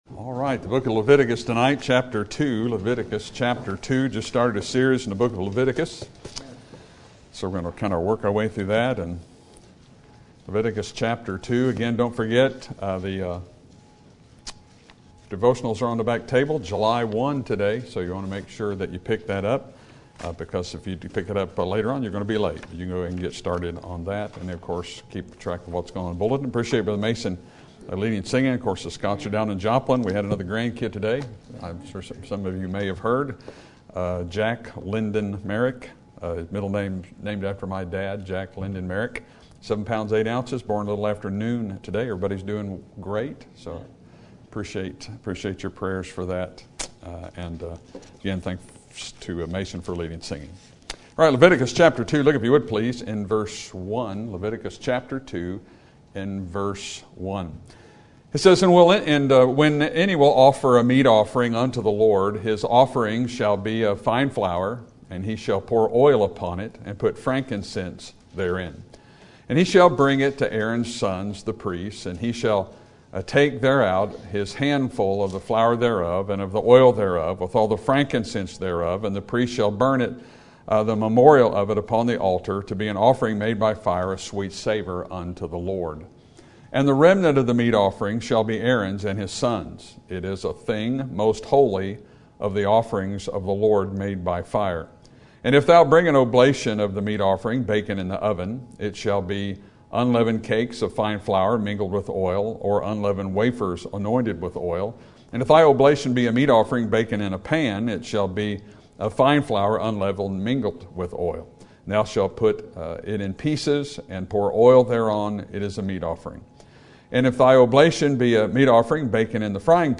Sermon Topic: How Can A Man Be Right With God? Sermon Type: Series Sermon Audio: Sermon download: Download (24.35 MB) Sermon Tags: Leviticus Burnt Offering Meat